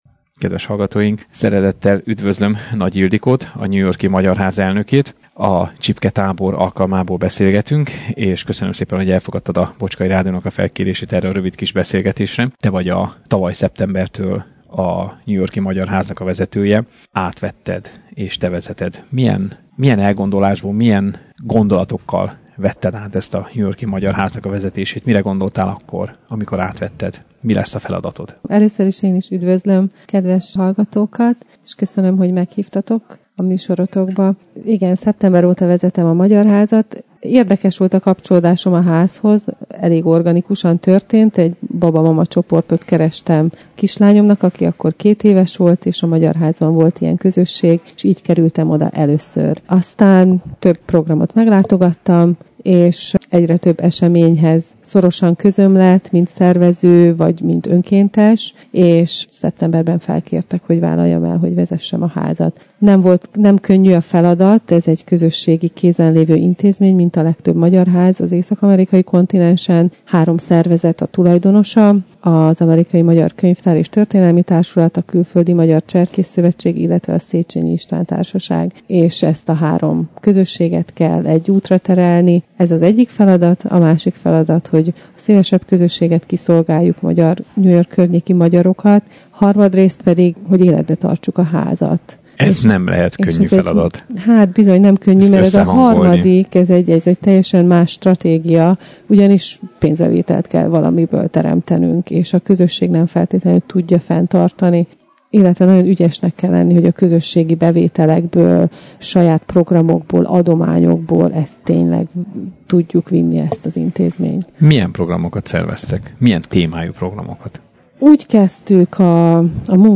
Kérdéseinkre készségesen válaszolt, a New York-i magyarokról beszélgettünk.